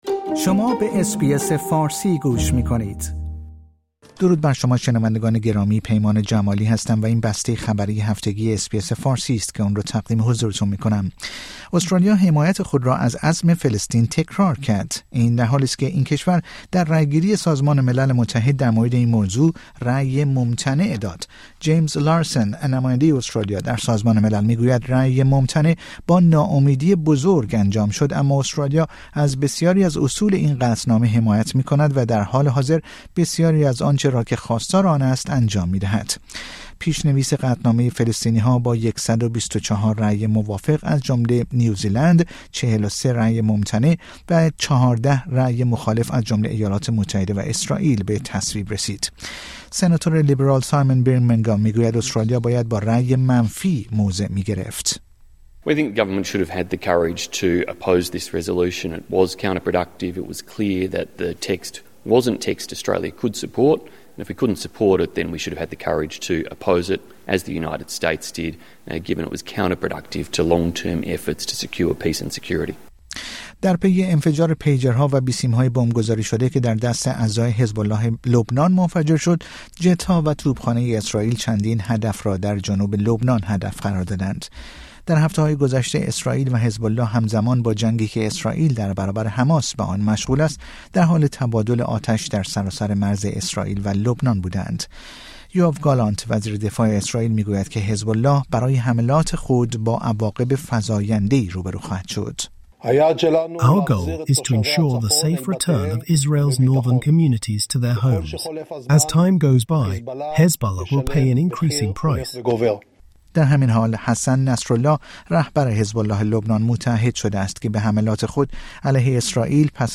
در این پادکست خبری مهمترین اخبار استرالیا، جهان و ایران در یک هفته منتهی به شنبه ۲۱ سپتامبر ۲۰۲۴ ارائه شده است.